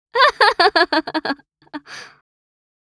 Download Free Laugh Sound Effects | Gfx Sounds
Teen-girl-joyful-laughter.mp3